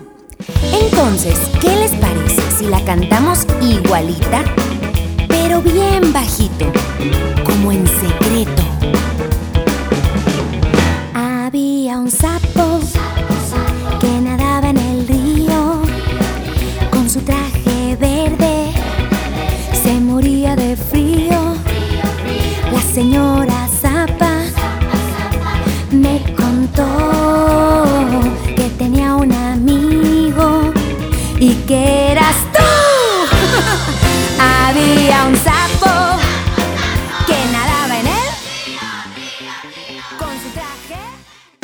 In 2009, she released another new children's album.